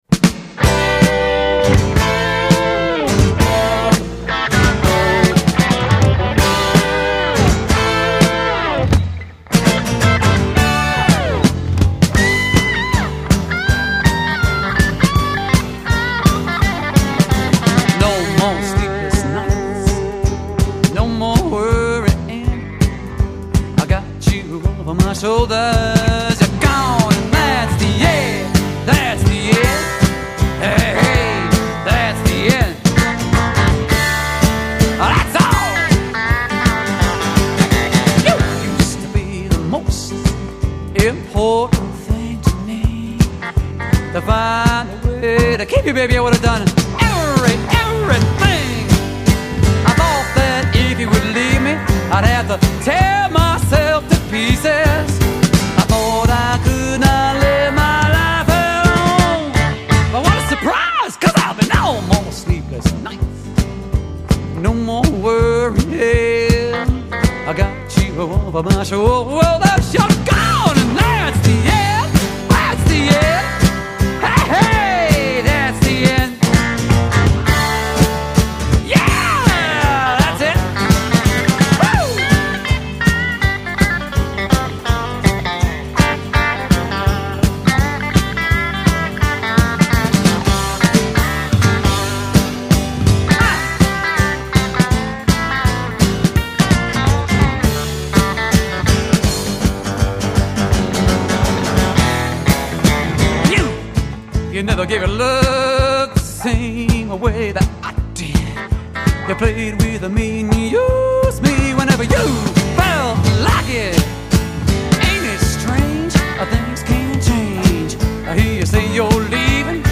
vocals, guitar
Bass
Drums
Telecaster